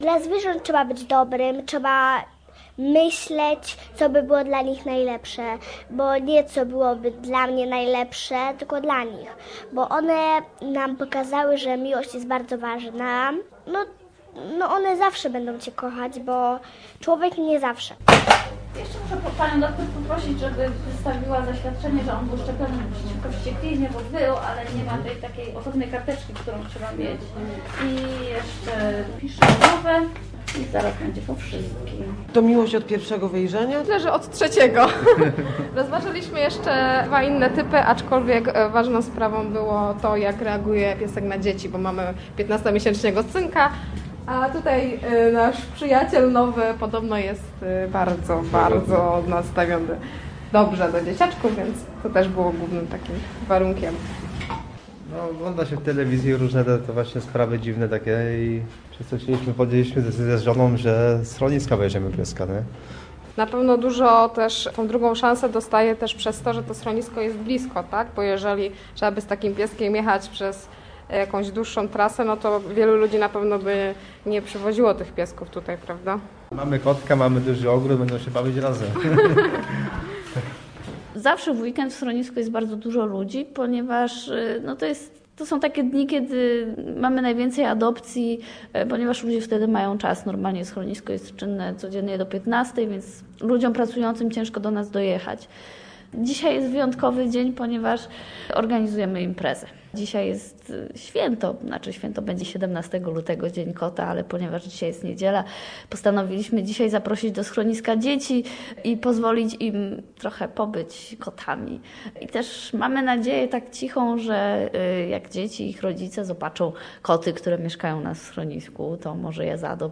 Wielka psota - reportaż
Można było poczuć się jak tropiący myszy kot, zmienić się w kota, skomponować kocią muzykę, ćwiczyć kocią zwinność, wreszcie można było zrobić zabawki dla "futrzaków" i osobiście im wręczyć. Tak świętowano Dzień Kota w Schronisku Dla Zwierząt przy ul. Bukowskiej w Poznaniu.